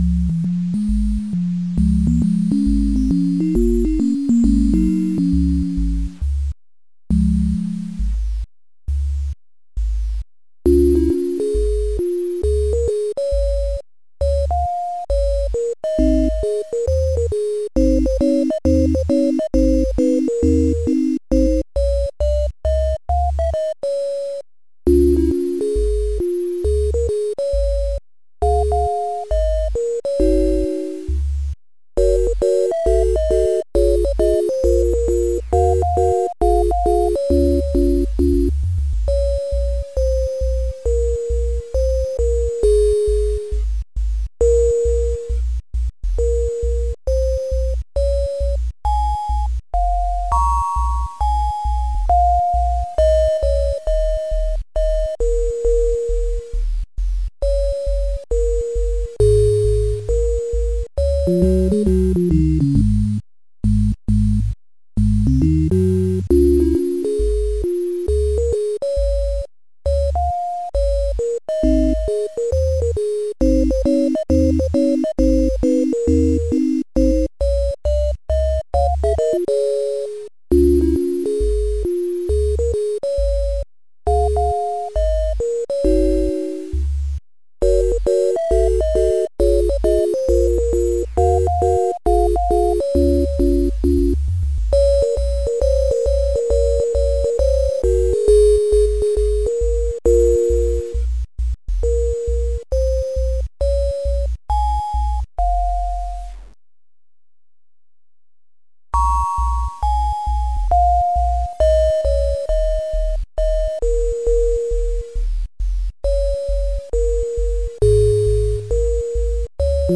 8bit Microcontroller MIDI Renderer
MIDIs converted using SimHC12 & MIDI Juke Box